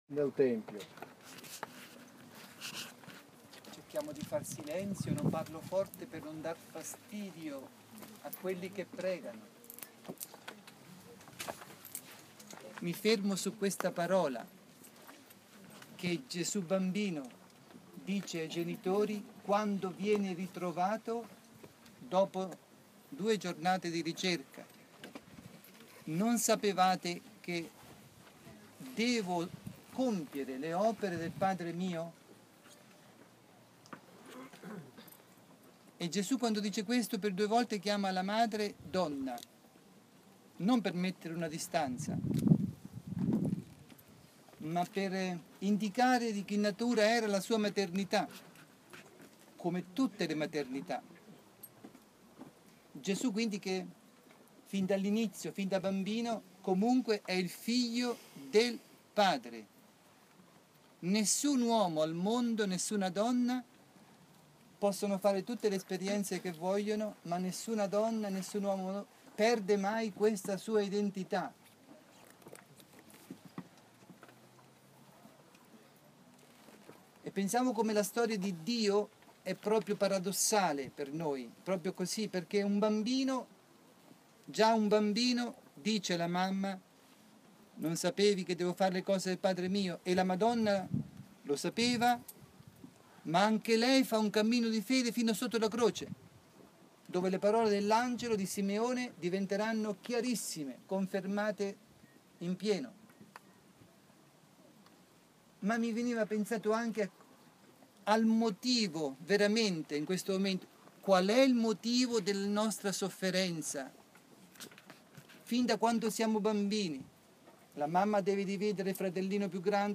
Pellegrinaggio a Medugorje del 15-20 maggio 2015
Sabato 16 maggio, ROSARIO  sulla collina delle apparizioni (Podbrdo)